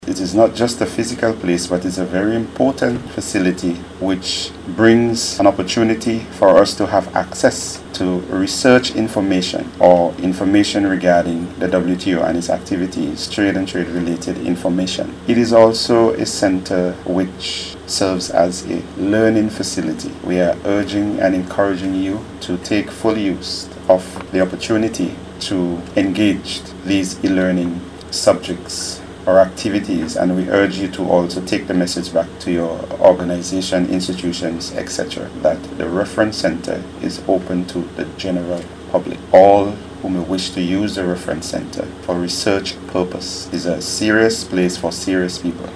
Giving remarks at the opening ceremony